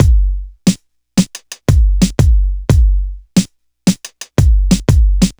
• 89 Bpm Drum Loop Sample G Key.wav
Free breakbeat sample - kick tuned to the G note. Loudest frequency: 1487Hz
89-bpm-drum-loop-sample-g-key-ffx.wav